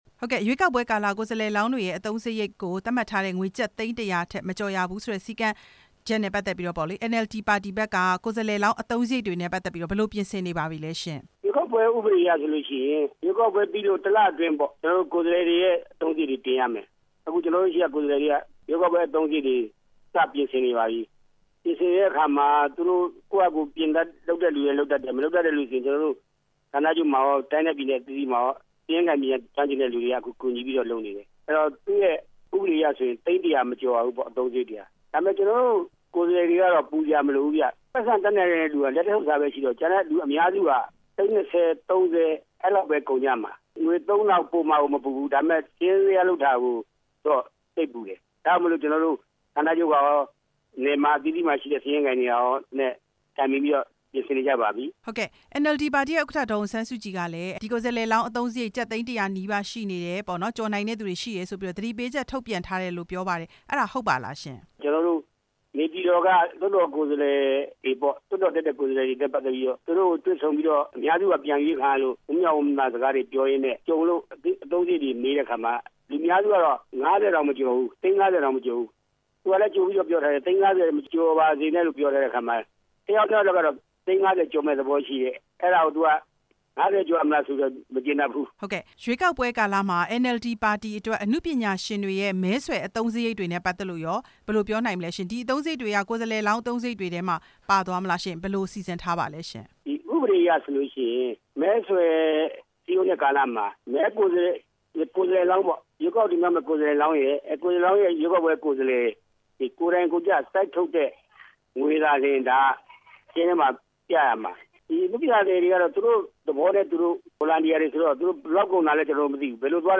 NLD ပါတီမဲဆွယ်စည်းရုံးရေး အသုံးစရိတ်အကြောင်းမေးမြန်းချက်